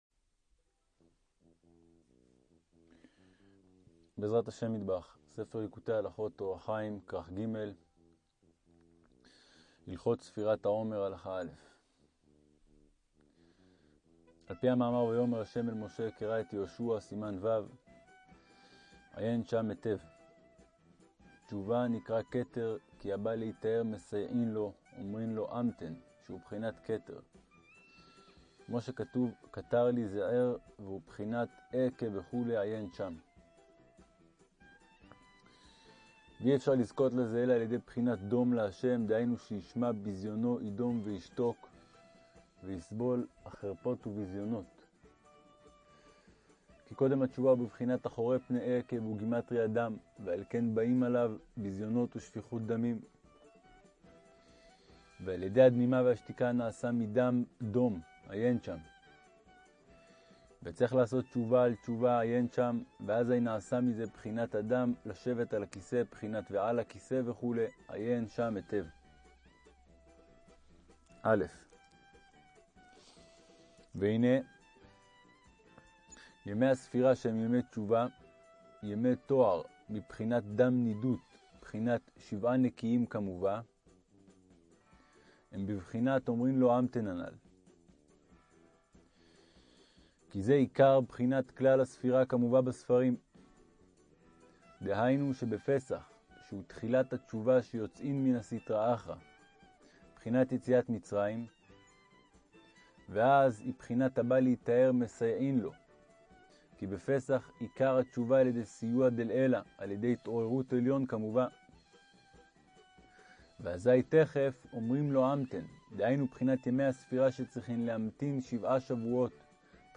ליקוטי-הלכות-אורח-חיים-ג-039-ספירת-העומר-א-ניגונים.mp3